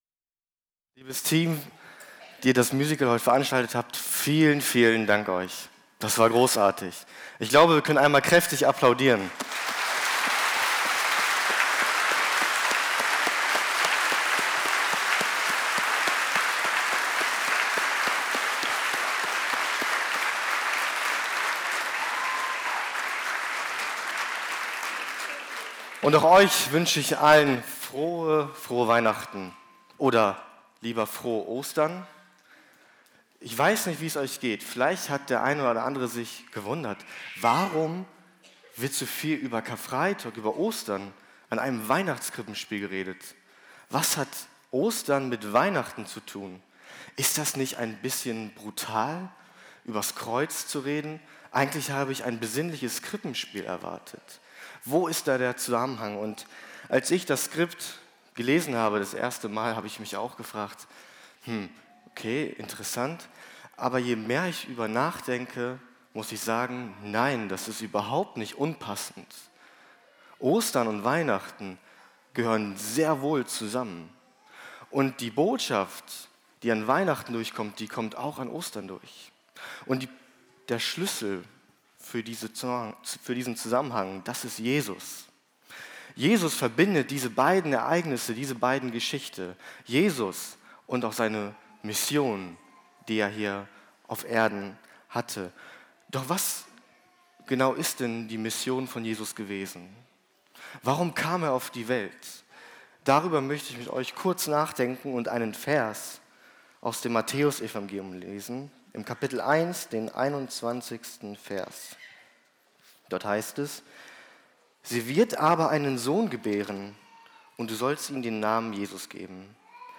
Kindermusical